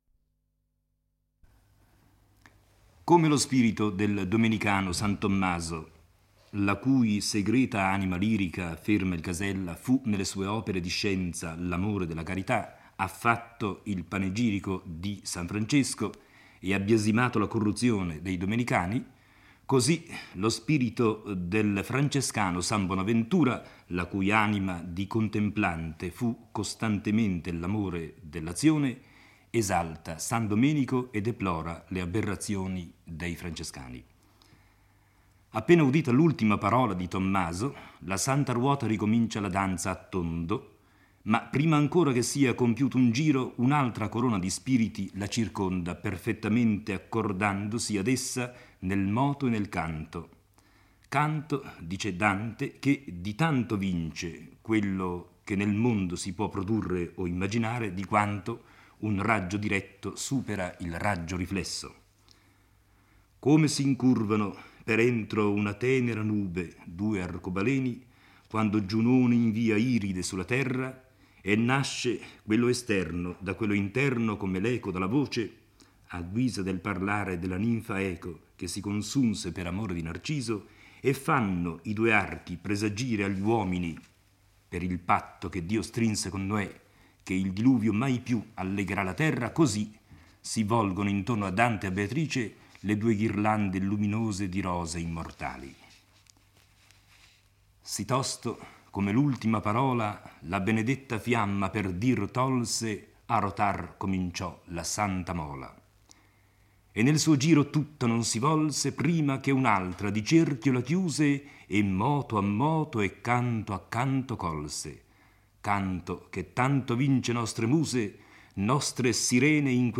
legge e commenta il XII canto del Paradiso. Uno spirito della seconda corona inizia a parlare: tratterà della carità di San Domenico, fondatore dell'ordine a cui è appartenuto San Tommaso.